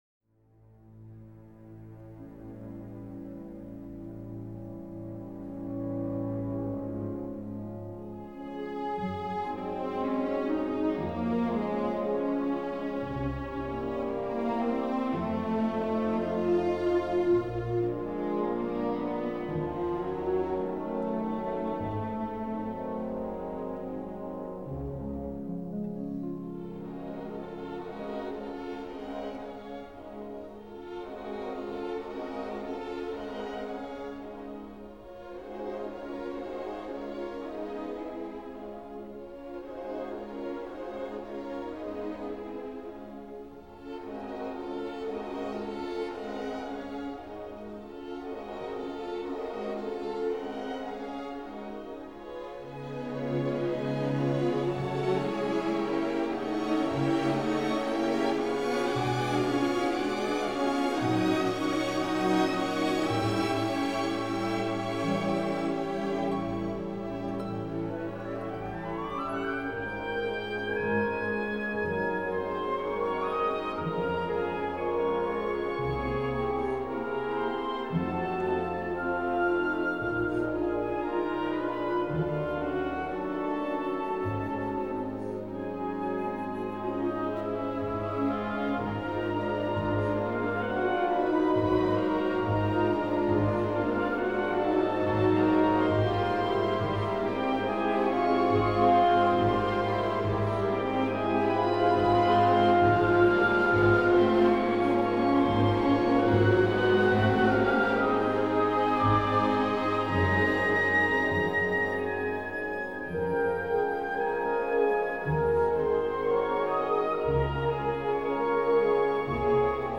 • Жанр: Джаз